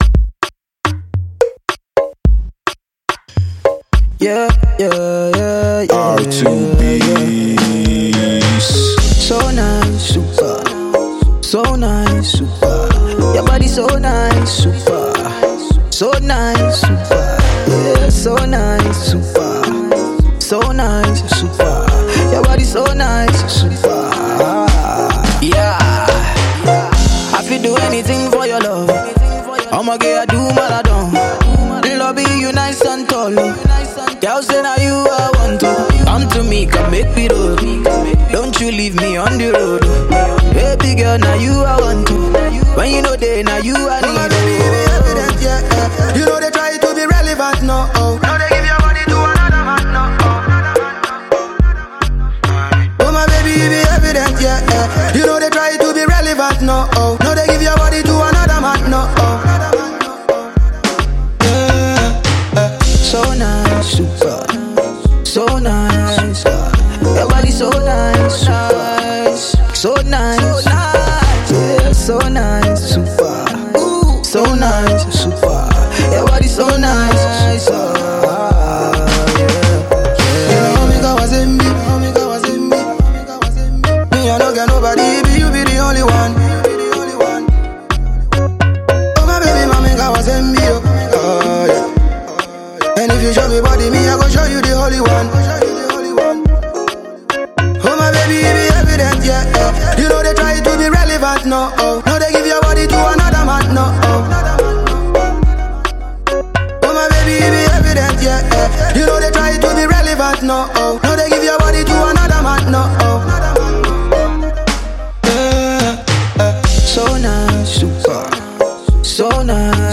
Ghanaian duo
Afro-pop